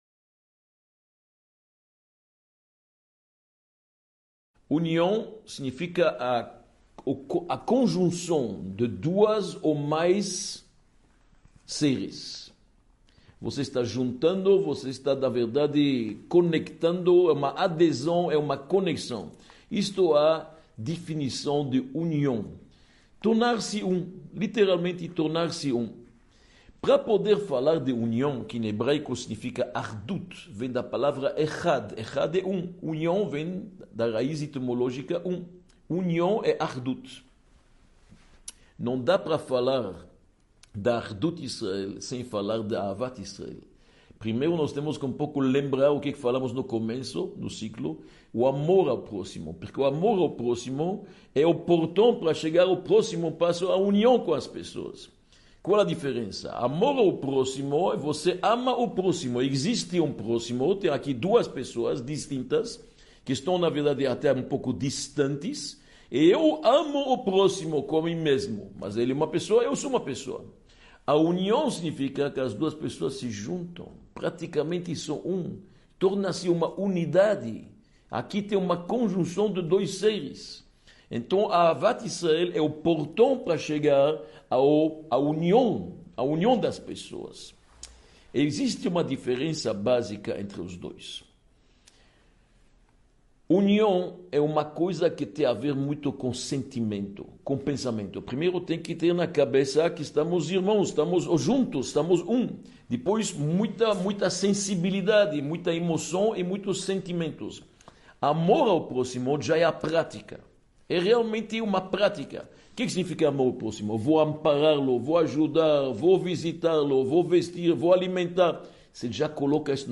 Aula 07 | Manual Judaico